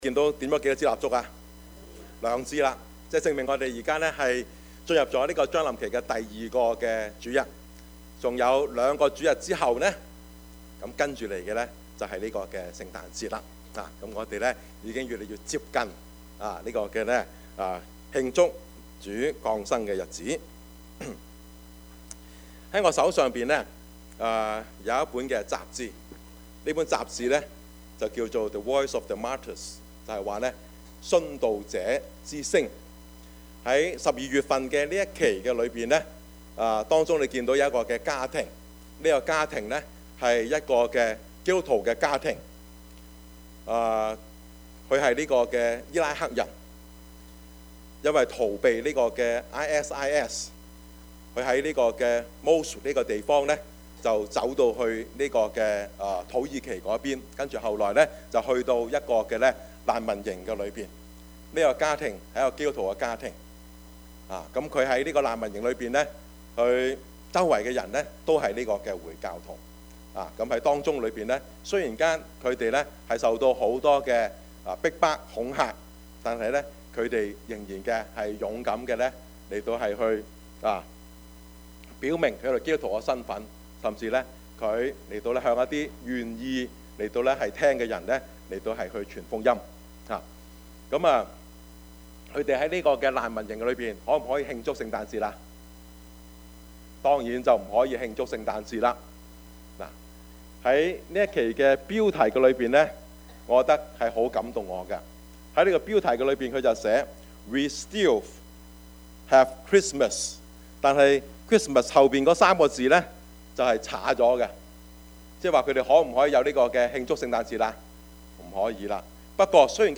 Service Type: 主日崇拜
Topics: 主日證道 « 十字架與喜樂 終點的喜樂 »